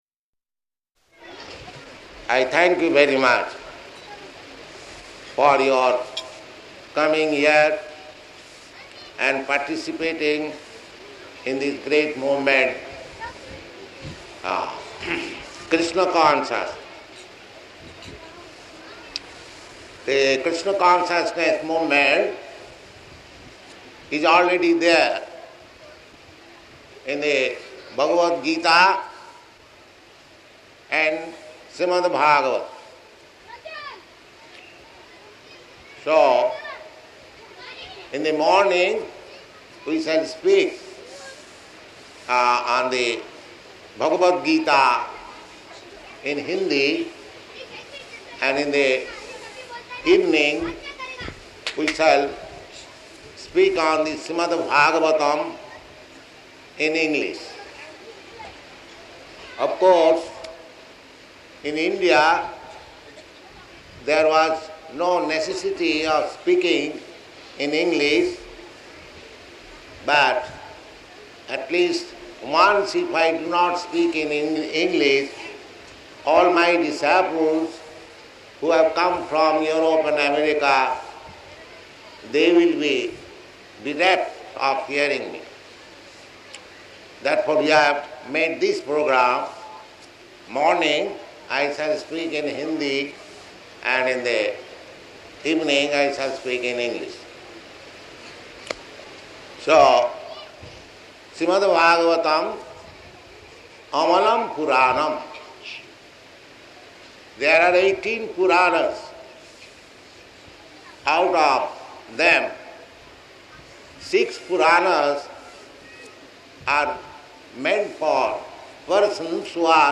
Pandal Lecture